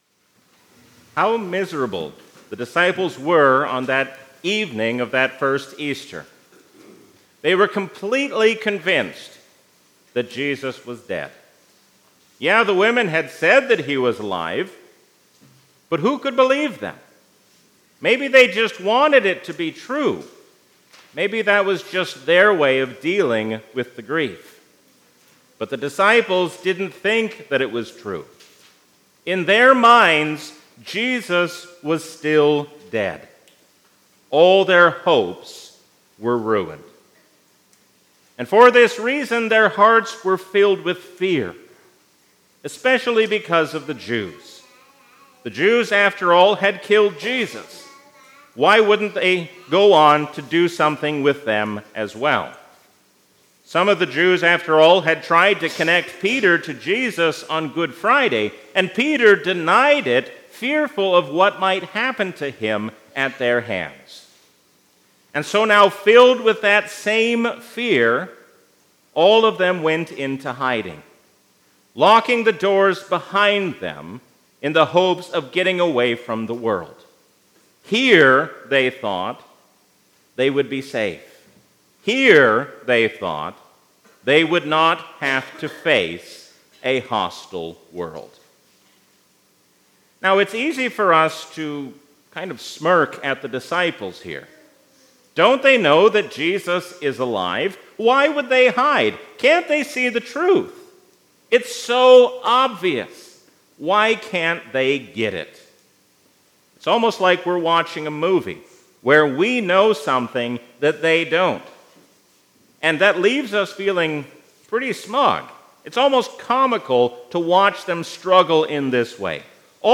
A sermon from the season "Easter 2023."